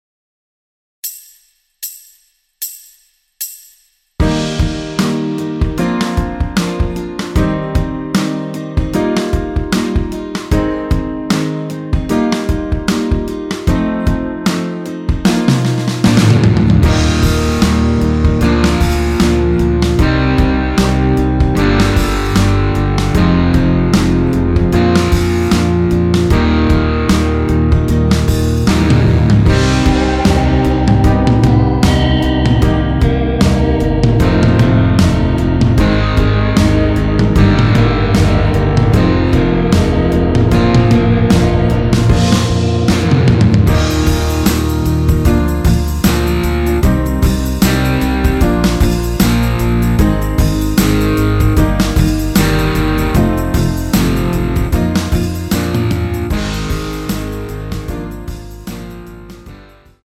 원키에서(-2)내린 MR입니다.
Eb
앞부분30초, 뒷부분30초씩 편집해서 올려 드리고 있습니다.
중간에 음이 끈어지고 다시 나오는 이유는